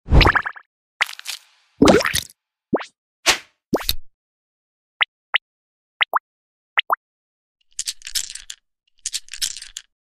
Surly SPRUNKI Squishy ASMR 😈🤭 Sound Effects Free Download